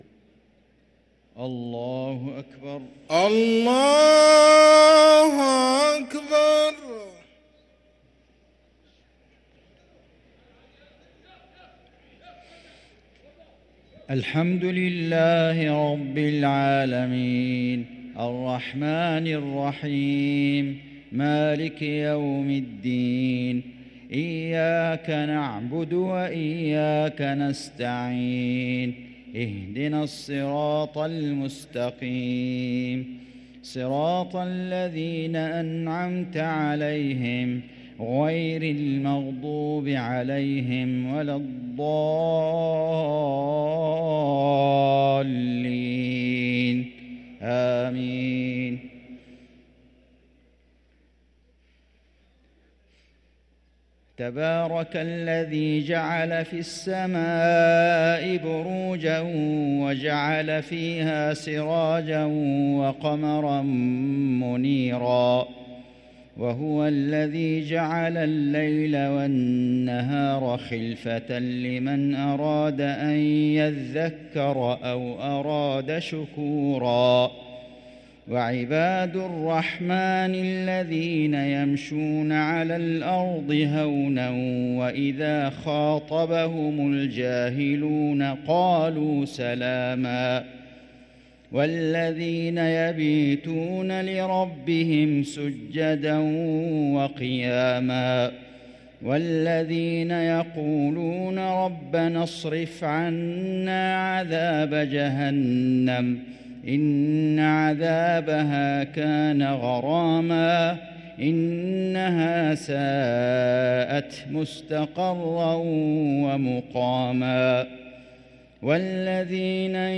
صلاة العشاء للقارئ فيصل غزاوي 12 شعبان 1444 هـ
تِلَاوَات الْحَرَمَيْن .